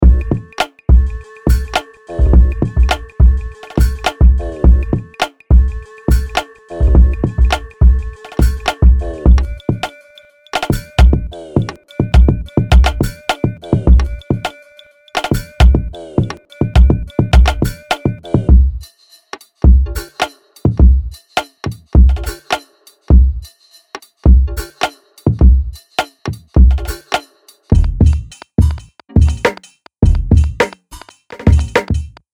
グルーヴィーなローファイ・バウンス、グリッドを超越するファンキー
・にじみ出るローファイ、遊び心、グリッドを超えるグルーブ感あふれるビート
プリセットデモ